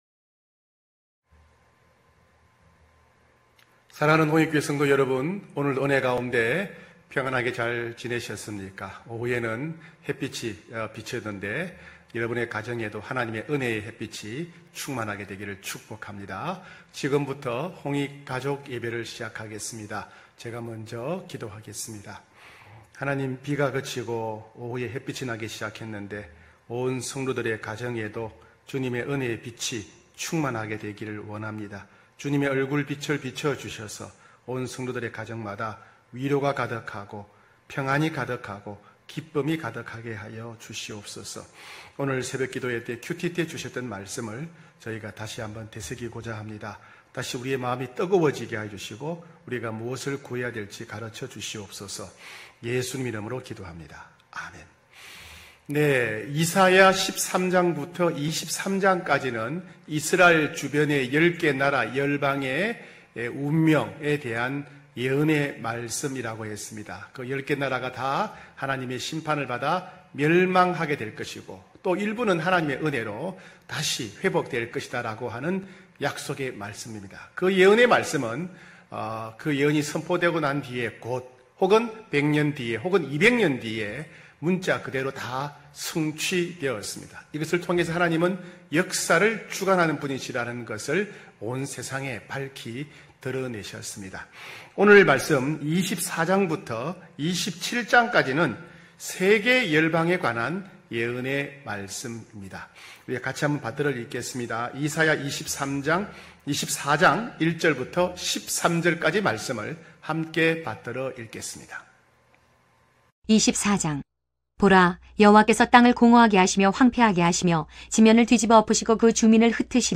9시홍익가족예배(8월11일).mp3